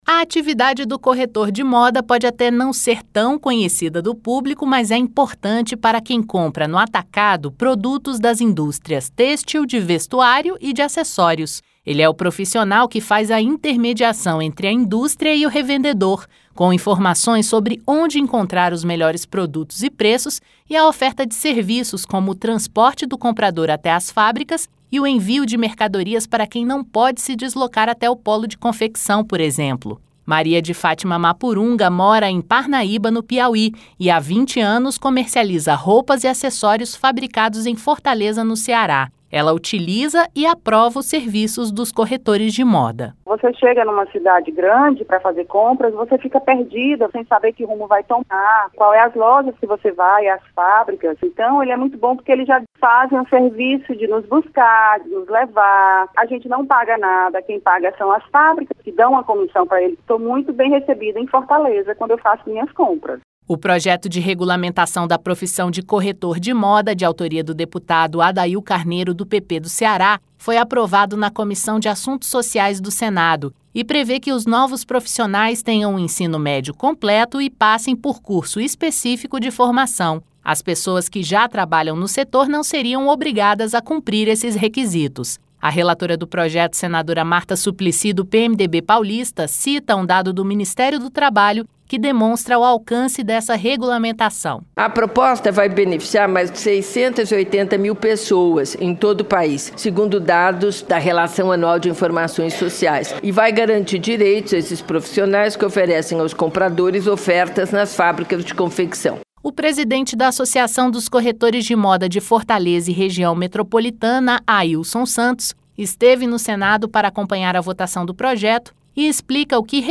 LOC: VOCÊ SABE O QUE FAZ ESSE PROFISSIONAL?